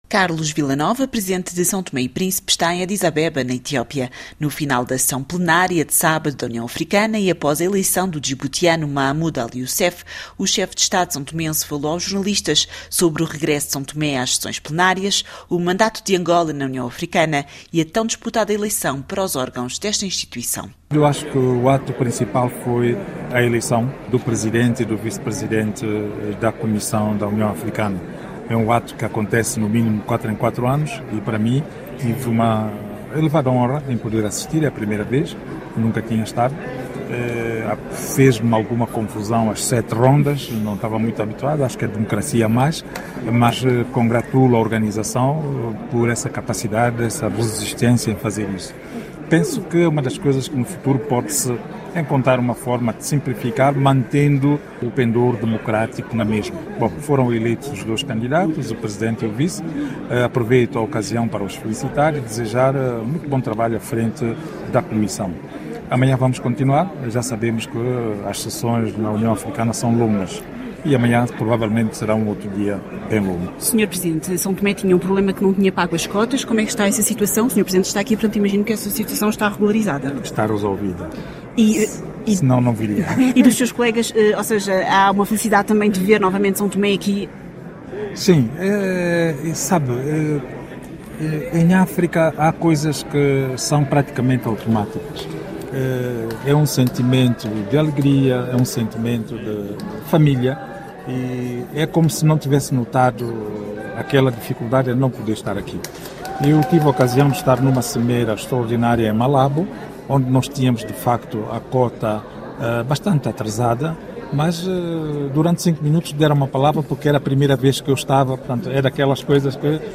De segunda a sexta-feira (ou, quando a actualidade o justifica, mesmo ao fim de semana), sob forma de entrevista, analisamos um dos temas em destaque na actualidade.